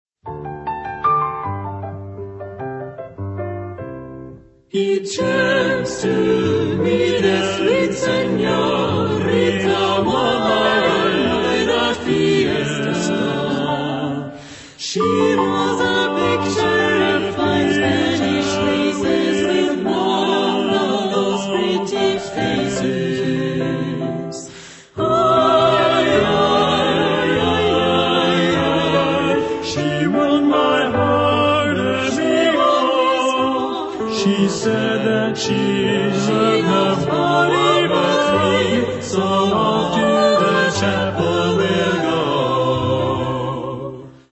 Carácter de la pieza : romántico
Tipo de formación coral: SATB  (4 voces Coro mixto )
Instrumentación: Piano  (1 partes instrumentales)
Tonalidad : do mayor